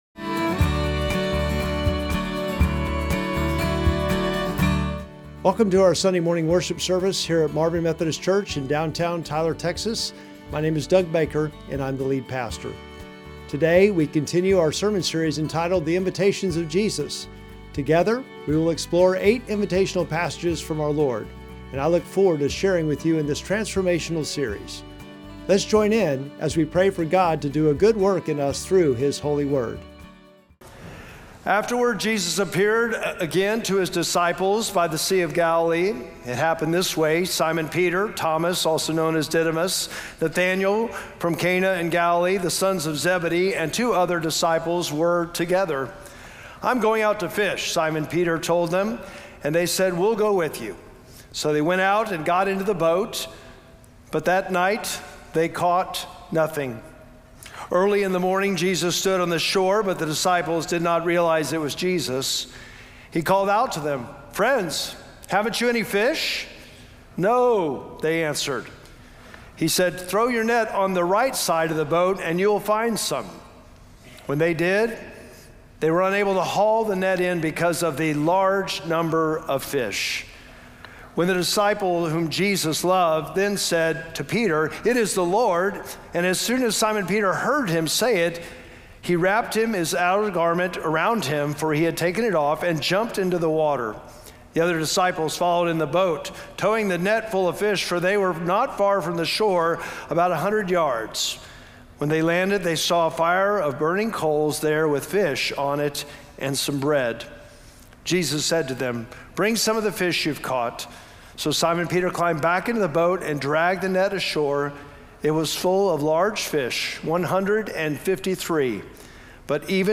Sermon text: John 21:1-14